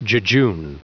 Prononciation du mot jejune en anglais (fichier audio)
Prononciation du mot : jejune